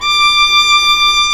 Index of /90_sSampleCDs/Roland L-CD702/VOL-1/STR_Viola Solo/STR_Vla1 % marc